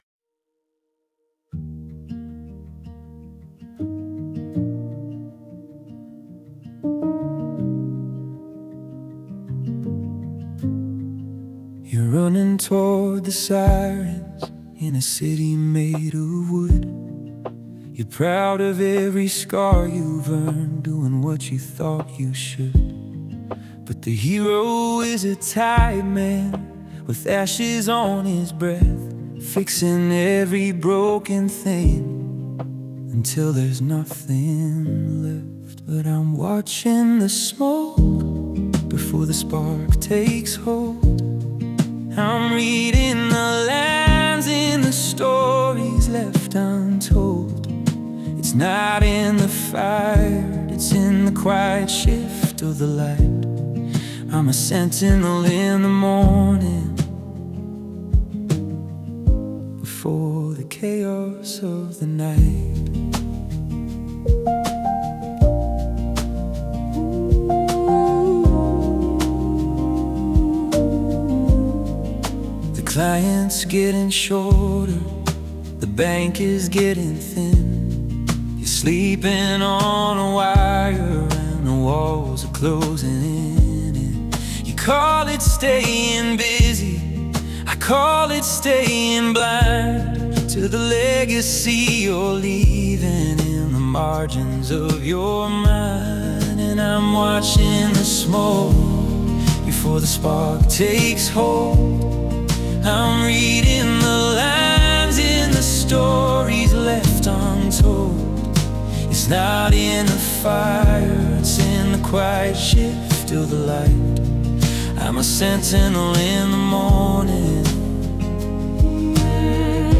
Atmospheric tracks for deep reflection.